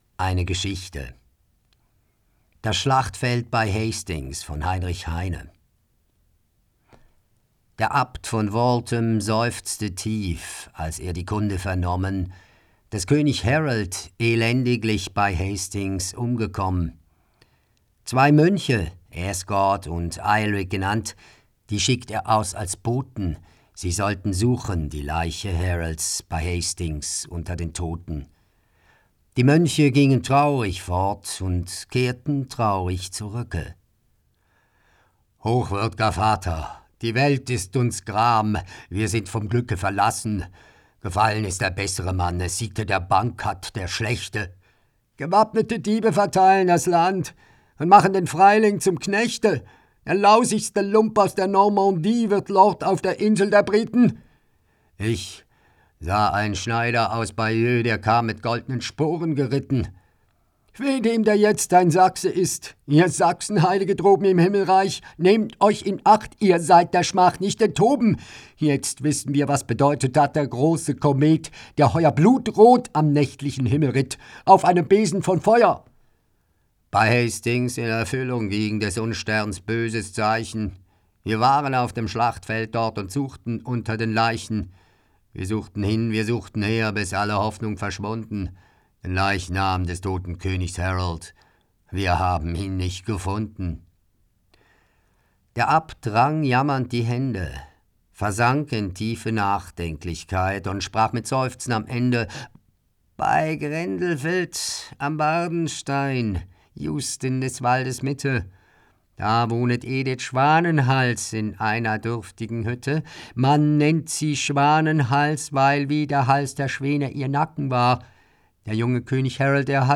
Schauspieler – Regisseur
Sprechproben:
Geschichte